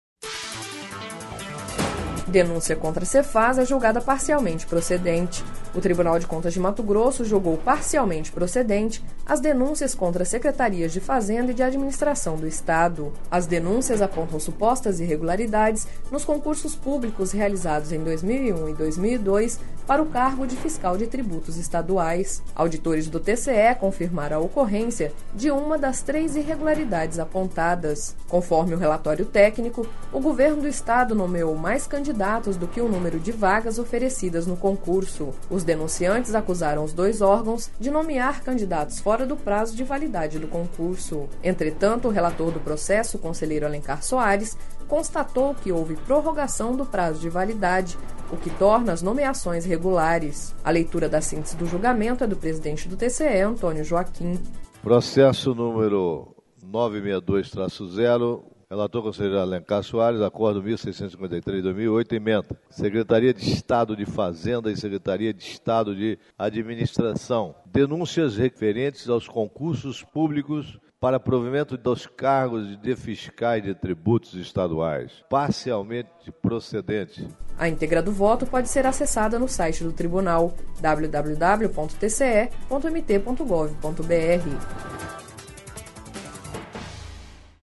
Sonora: Antonio Joaquim – conselheiro presidente do TCE-MT